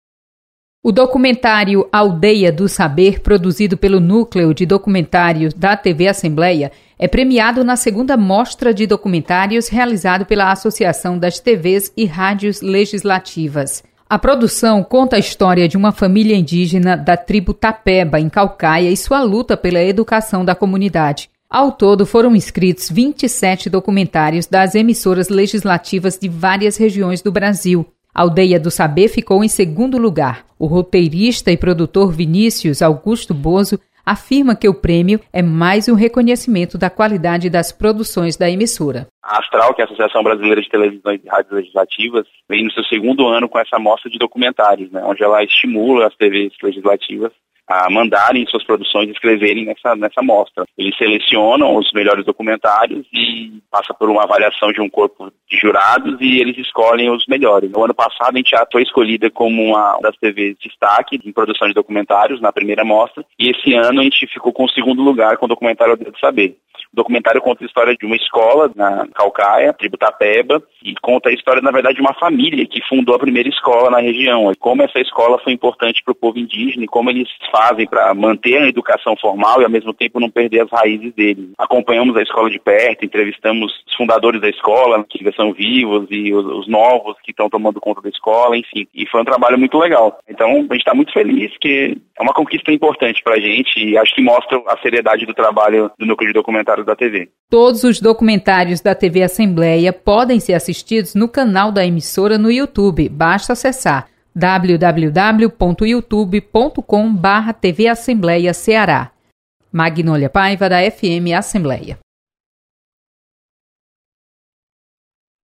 Documentário da TV Assembleia conquista segundo lugar em prêmio nacional. Repórter